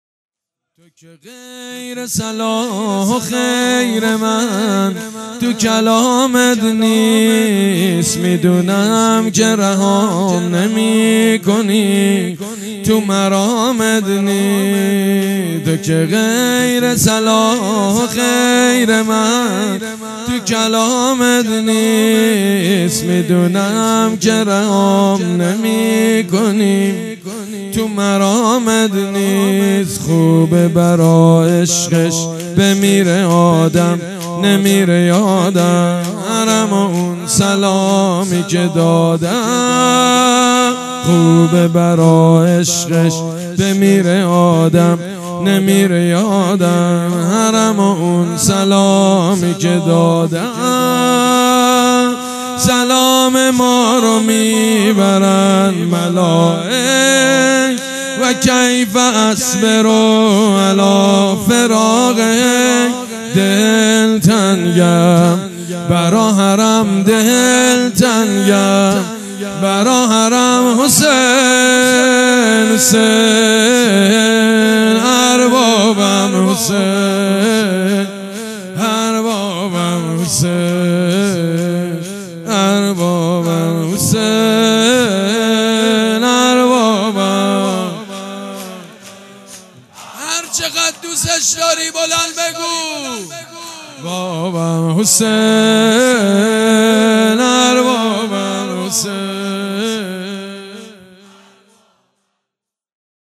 مراسم ایام شهادت حضرت زهرا(س) می دونم که ..